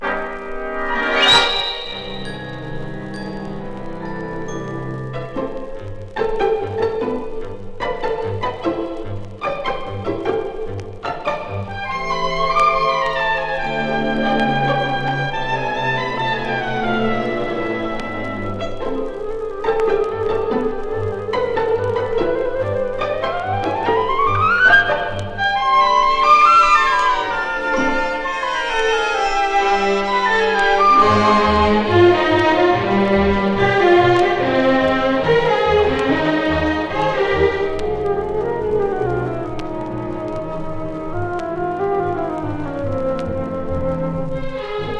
Jazz, Pop, Stage & Screen　USA　12inchレコード　33rpm　Mono